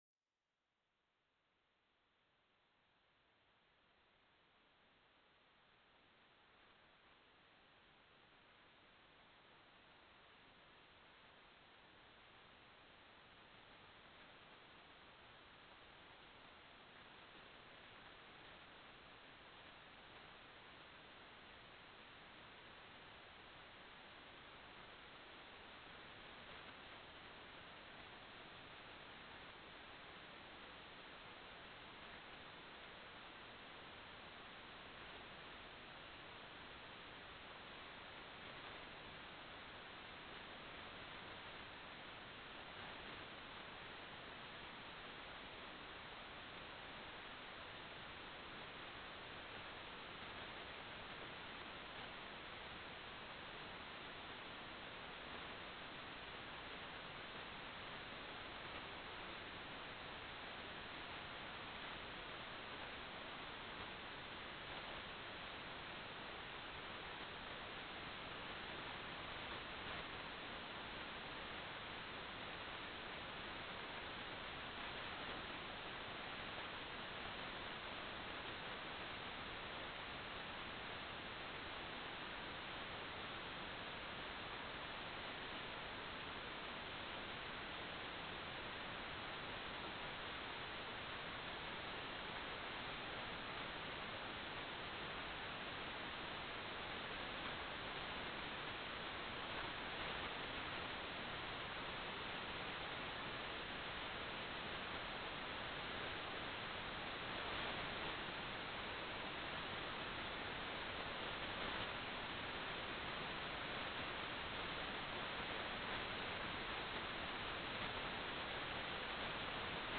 "transmitter_mode": "CW",